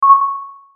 sonar.wav